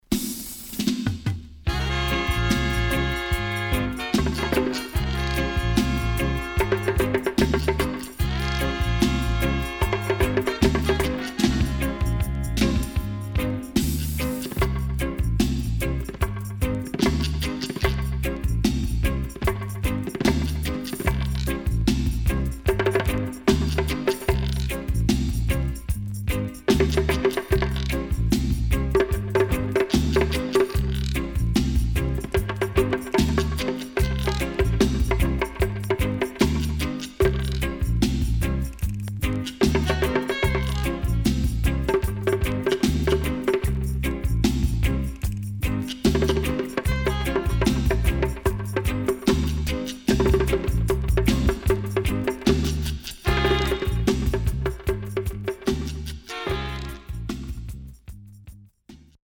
SIDE A:少しチリノイズ入りますが良好です。